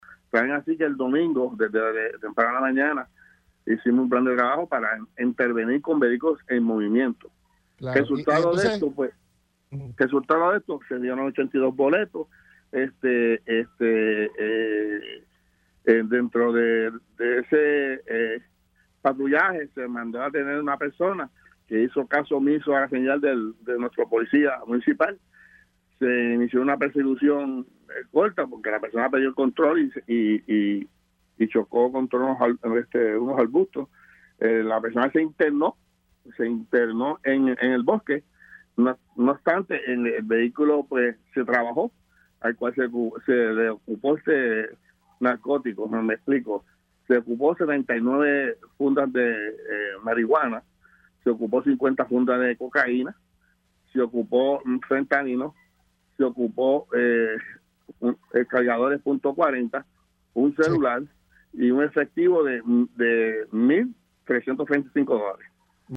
201-ALVIN-RODRIGUEZ-COMISIONADO-POLICIA-MOROVIS-INTERVINIERON-CON-VEHICULOS-EL-DOMINGO-HUBO-PERSECUSION-CON-UN-AUTO.mp3